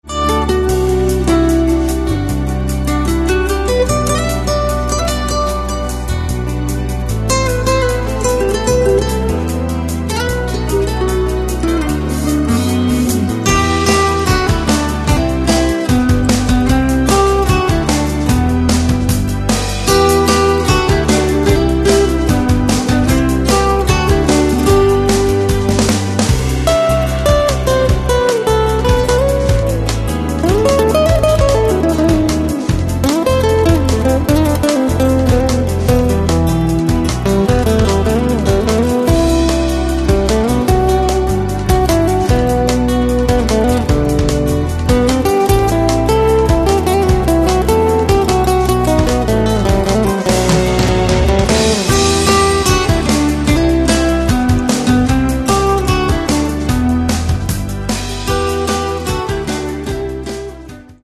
Catalogue -> Rock & Alternative -> Simply Rock